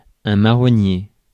Ääntäminen
Synonyymit marronnier d'Inde Ääntäminen France: IPA: /ma.ʁɔ.nje/ Haettu sana löytyi näillä lähdekielillä: ranska Käännös 1. ке́стен {m} Suku: m .